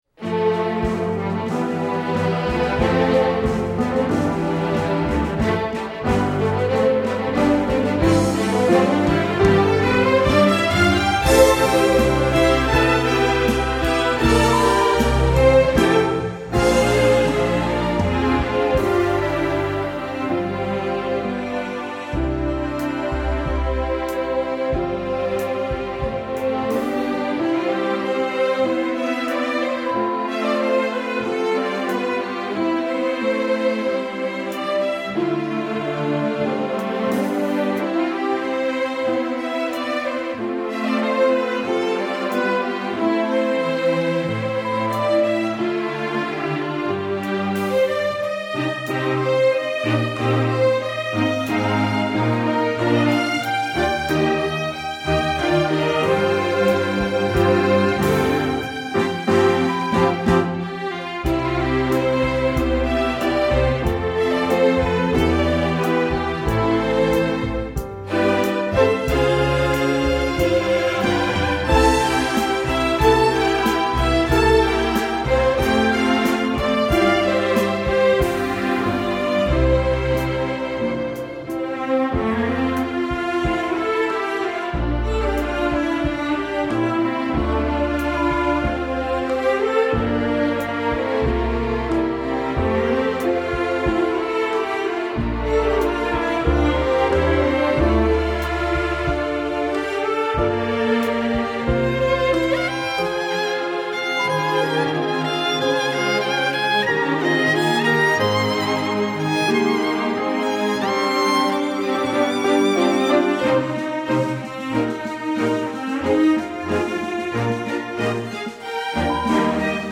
für Streichorchester
Besetzung: Streichorchester